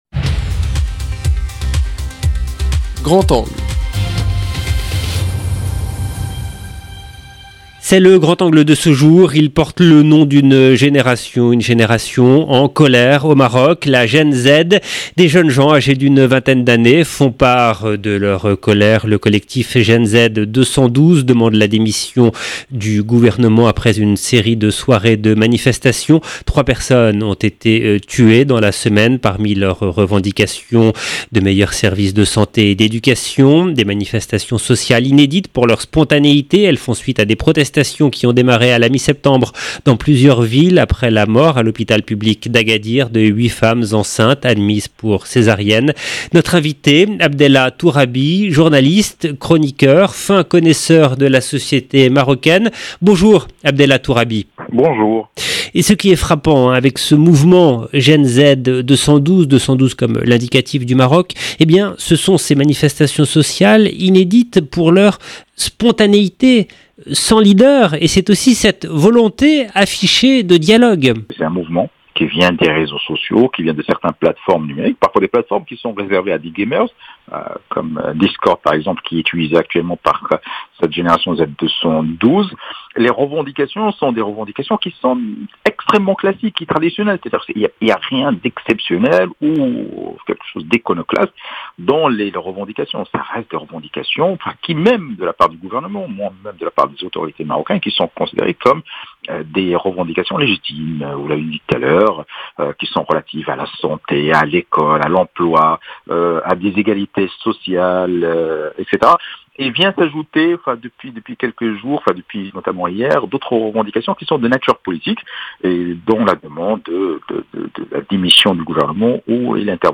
journaliste, chroniqueur, fin connaisseur de la société marocaine. 0:00 12 min 3 sec